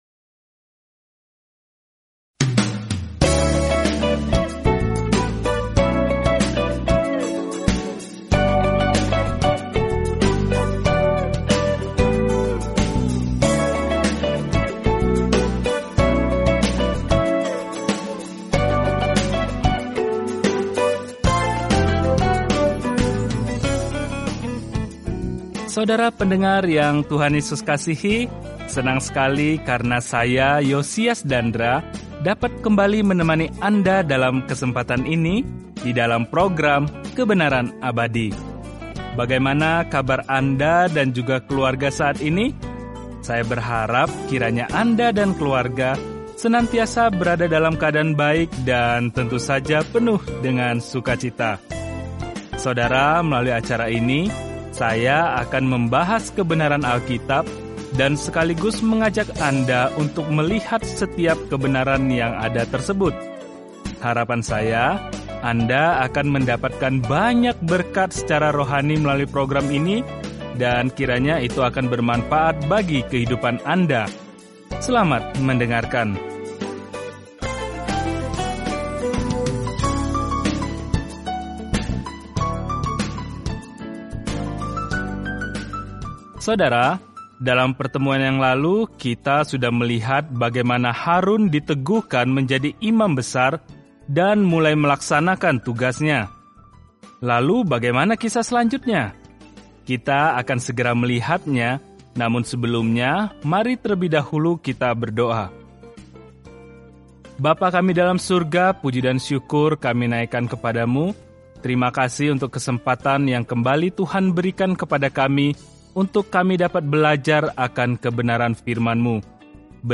Dalam ibadah, pengorbanan, dan rasa hormat, Imamat menjawab pertanyaan itu bagi Israel zaman dahulu. Jelajahi Imamat setiap hari sambil mendengarkan studi audio dan membaca ayat-ayat tertentu dari firman Tuhan.